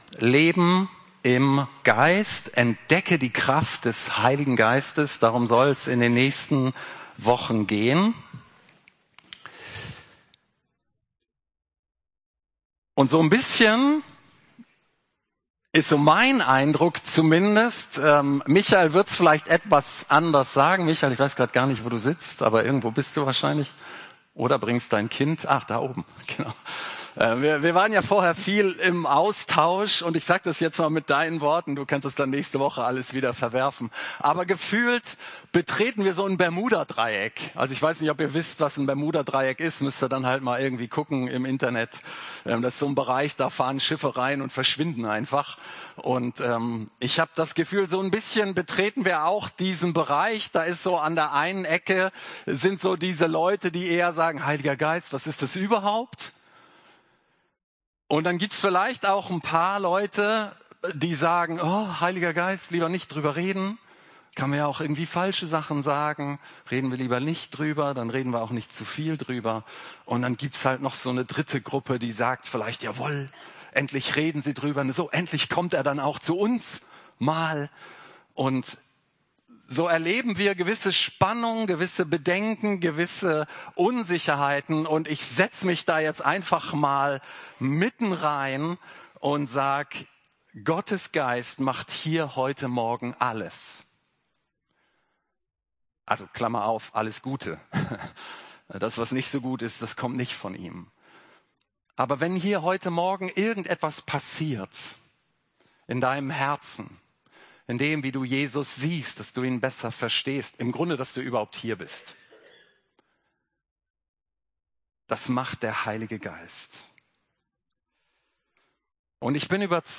Predigt als MP3 Vorbereitungshilfe Bibelstellen Alle bisher erschienenen Vorbereitungshilfen und Predigten findet ihr gesammelt unter: Leben im Geist 2026